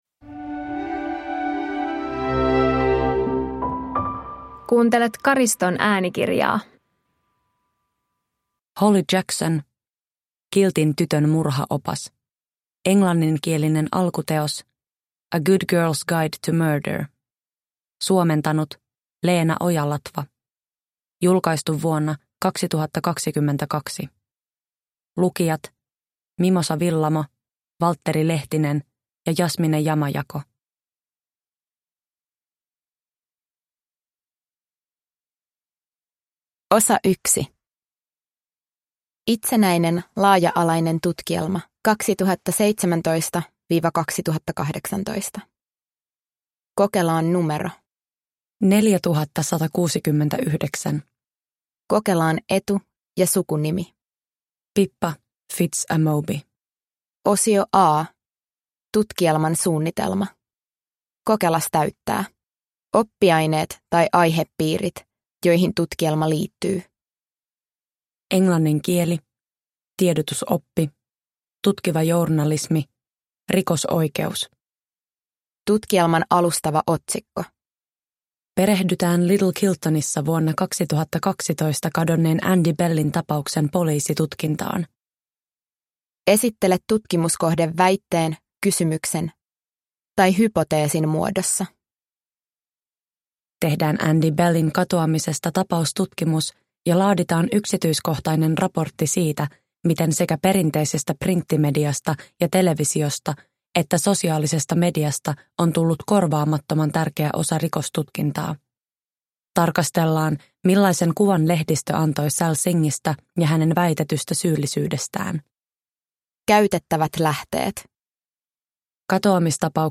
Kiltin tytön murhaopas – Ljudbok – Laddas ner